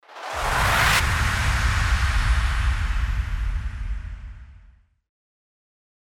FX-1570-WIPE
FX-1570-WIPE.mp3